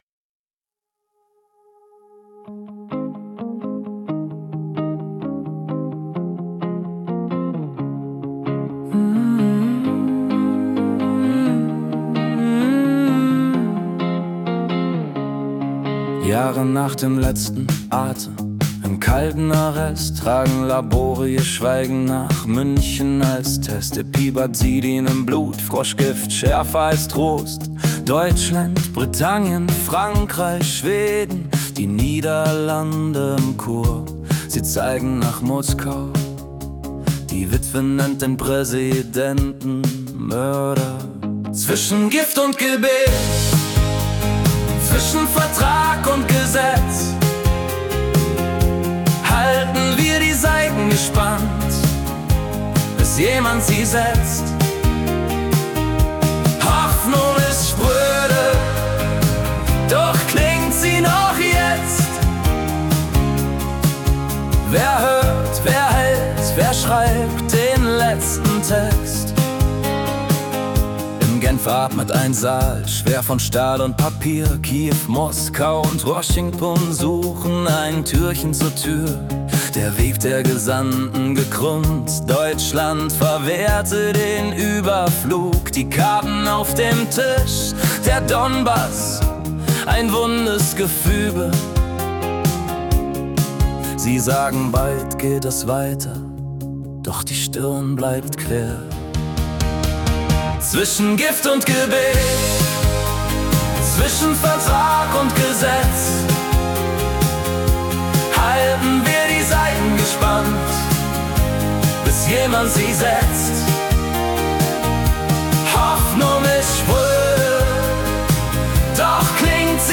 Februar 2026 als Singer-Songwriter-Song interpretiert.